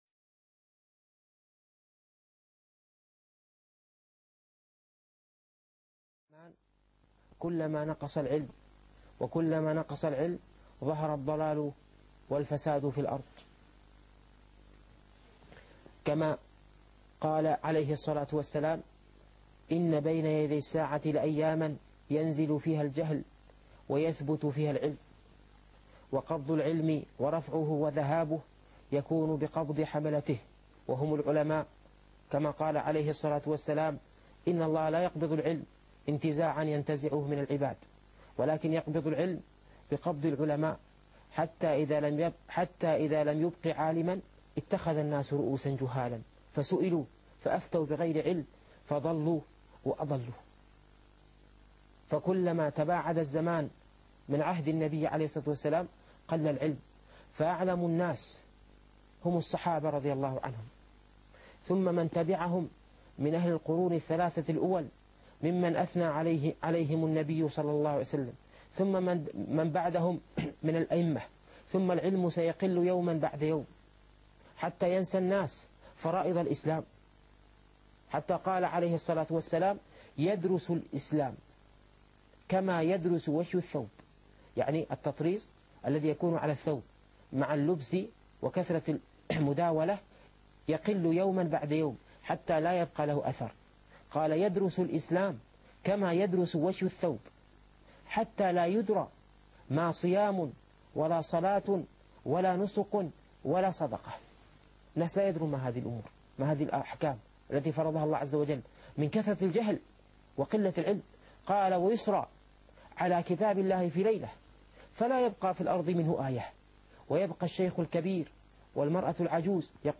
الفقه الميسر - الدرس الأول